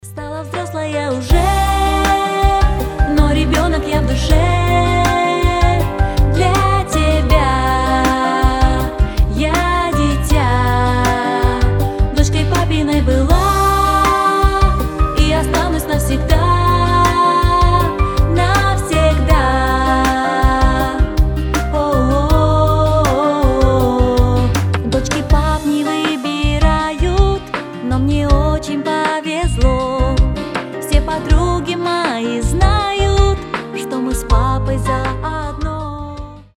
• Качество: 320, Stereo
женский голос
медленные
добрые